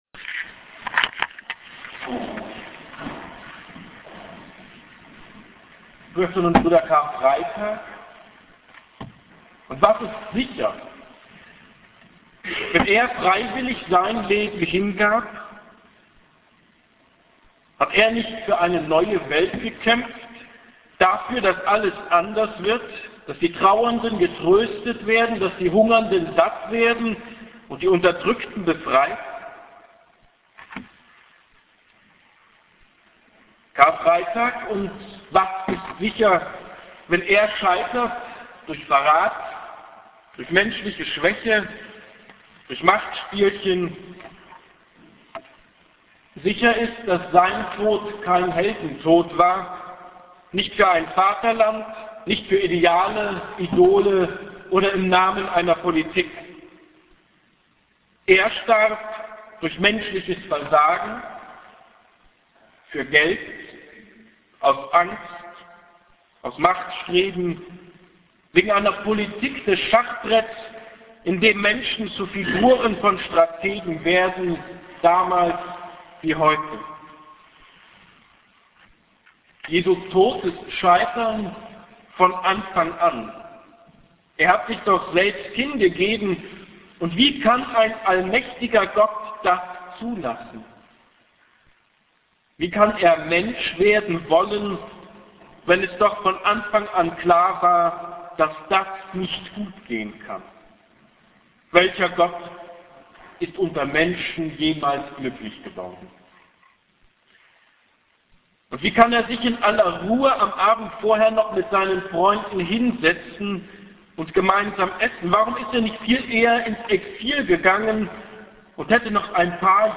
Karfreitag_hier klickt die Predigt